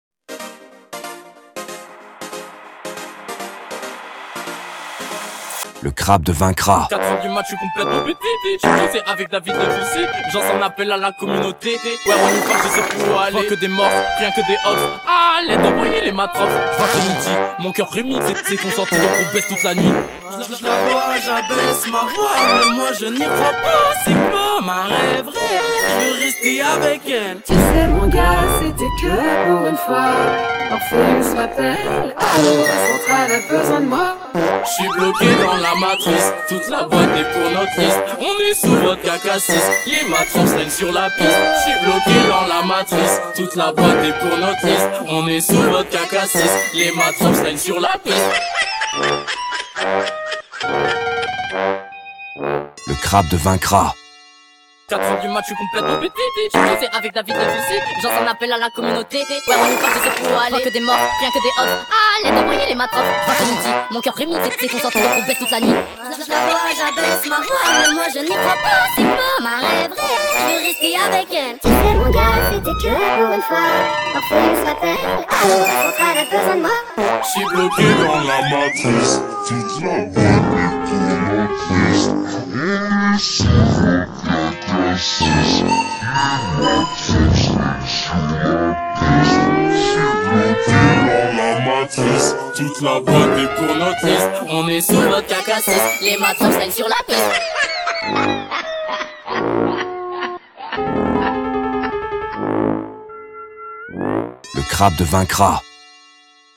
🎵 Hymne du CRABDE